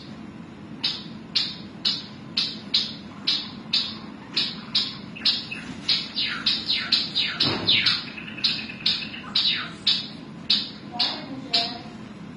白腰文鸟叫声、十姐妹报警鸣叫声